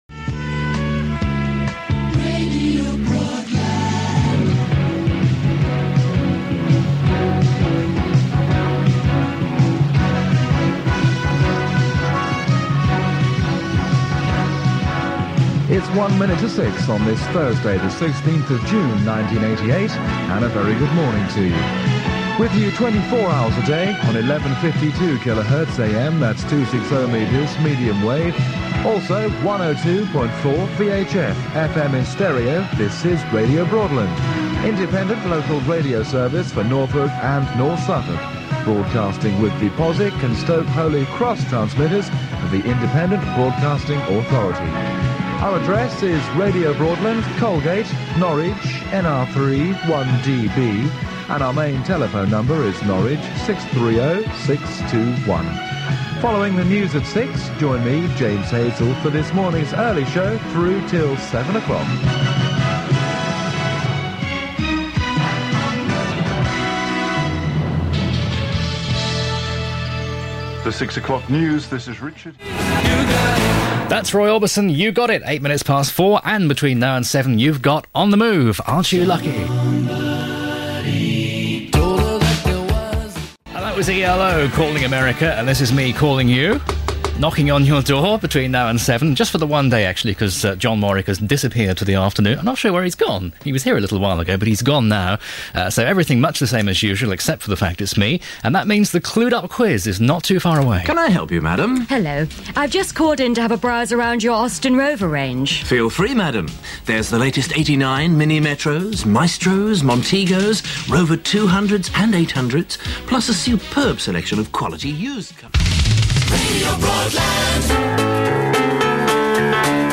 Enjoy it here in 88 and 89, with a suitably relaxed and polished offering as befitted the area, the station and the time. Enjoy the local ads with long-forgotten car models, and the halcyon days where if you got the wrong number of contestants on-air for a quiz, it mattered not.